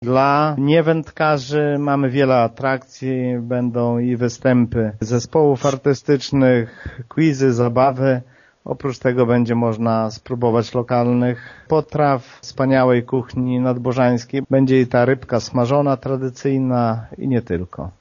Wójt Szopiński zaprasza do Prehoryłego nie tylko wędkarzy: